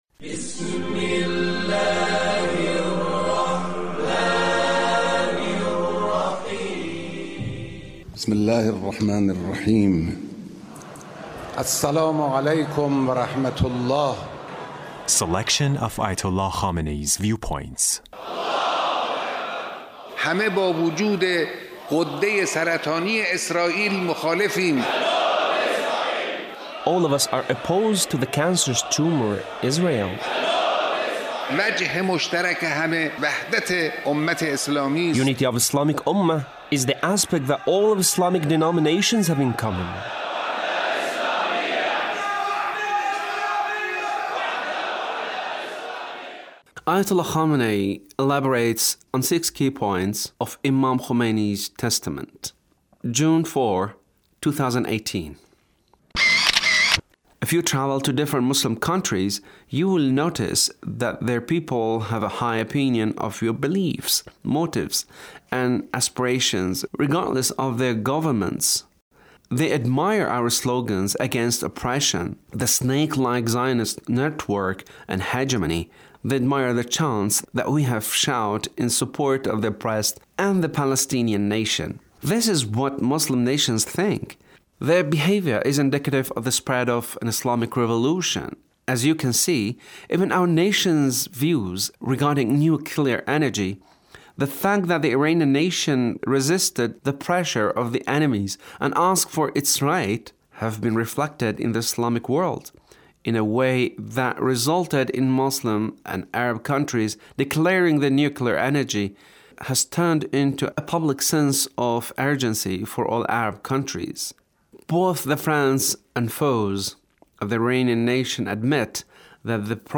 Leader's speech (1418)
The Leader's speech on Imam Khomeini and The Revolution